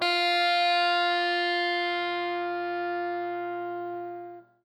SPOOKY    AO.wav